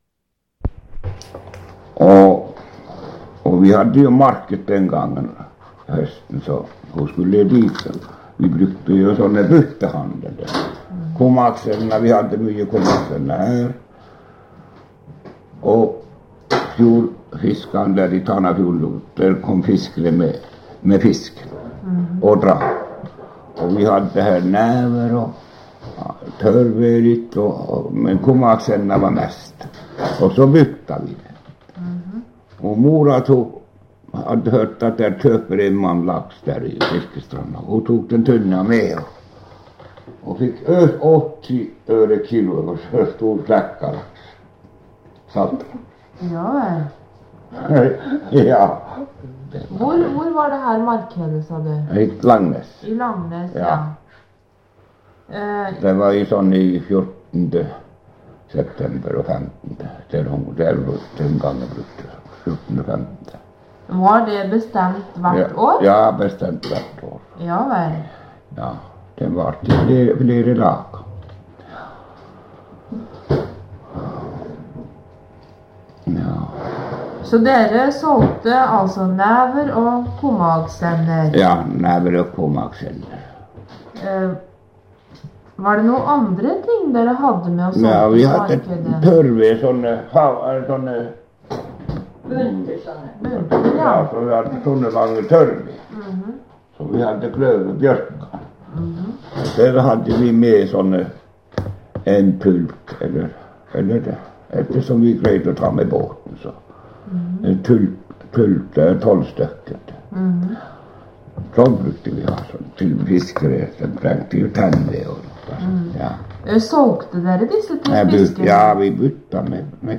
Sted: Tana, Vestre Seida